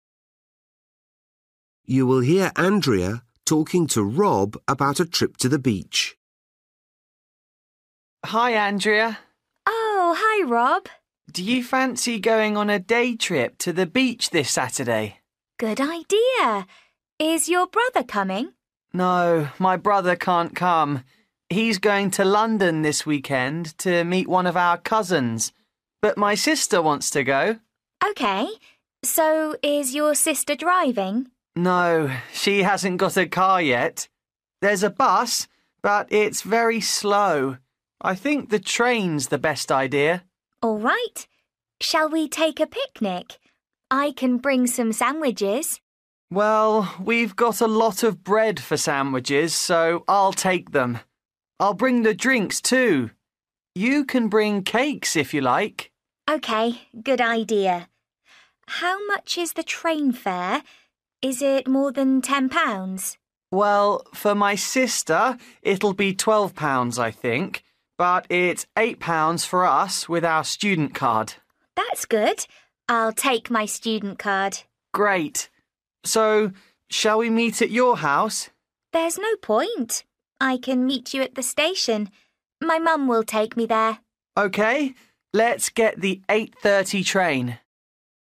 Bài tập trắc nghiệm luyện nghe tiếng Anh trình độ sơ trung cấp – Nghe một cuộc trò chuyện dài phần 44